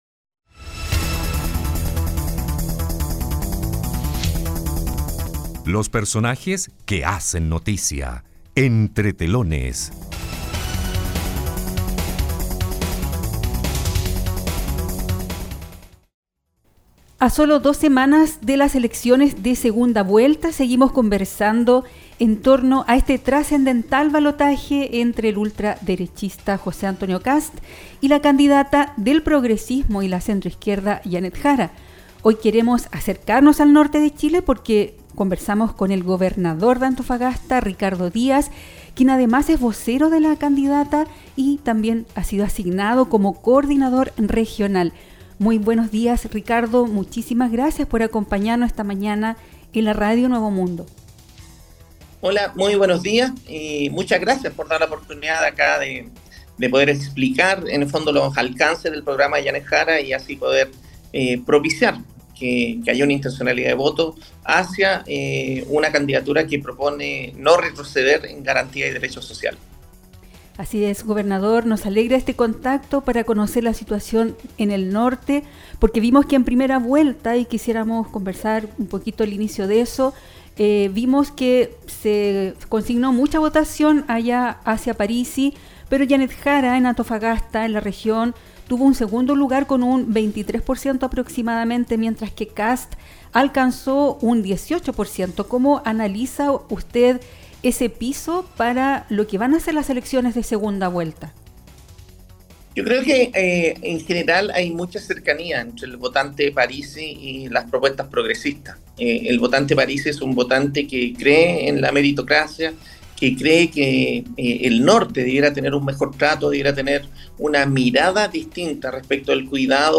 El Gobernador de Antofagasta, Ricardo Díaz, quien además es vocero de la candidata presidencial Jeannette Jara y coordinador regional del comando, conversó con la radio apuntando a la alta votación que obtuvo Parisi en las elecciones en primera vuelta y que fue seguido por la abanderada de la centro izquierda.